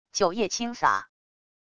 酒液轻洒wav音频